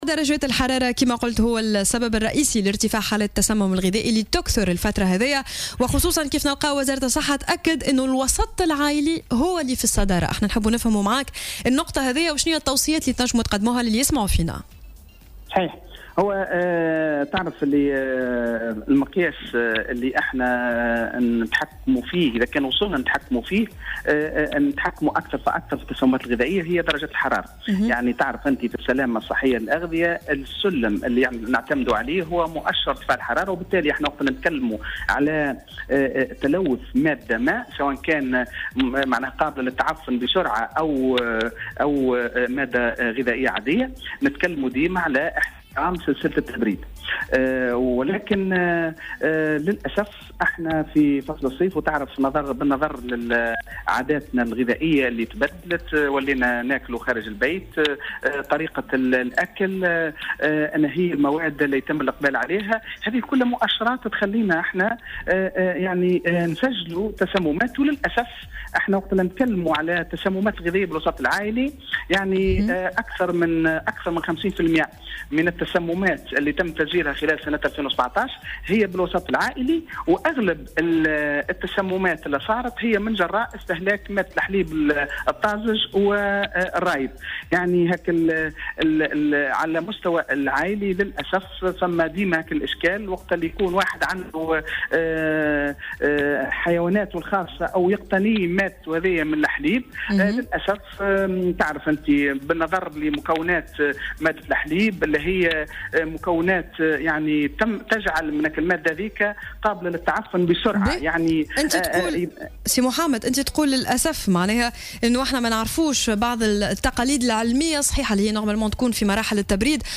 وأضاف في مداخلة له اليوم في برنامج "صباح الورد" على "الجوهرة أف أم" أن أكثر من 50 بالمائة من هذه الحالات تم تسجيلها في الوسط العائلي جراء استهلاك الحليب الطازج.